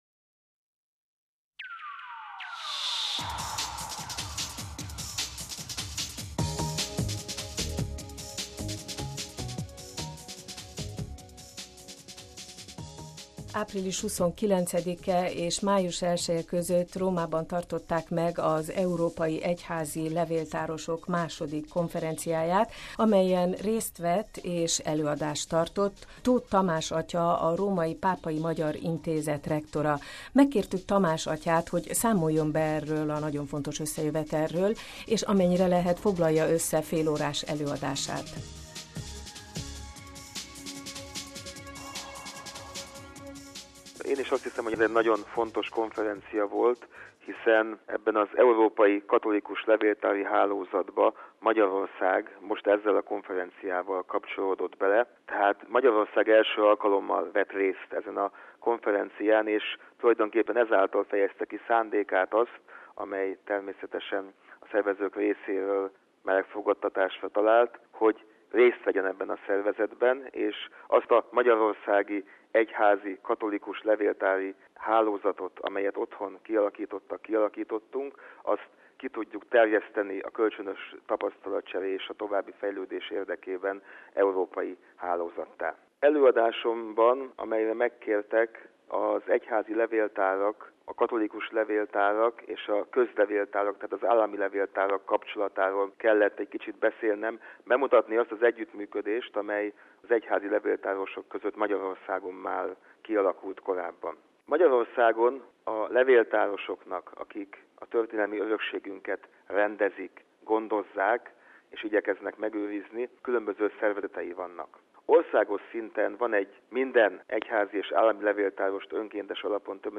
„Megőrizni és érvényre juttatni” – interjú